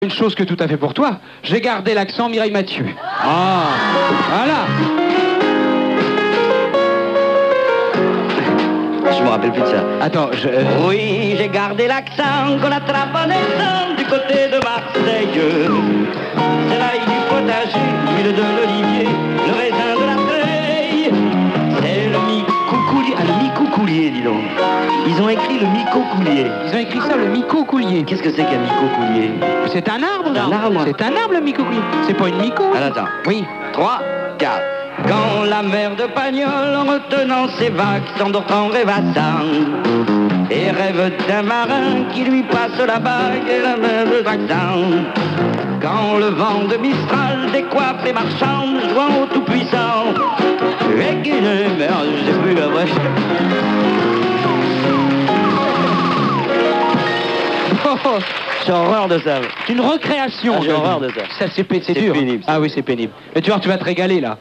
Chantera, chantera pas (séquences RTL 1983-1985)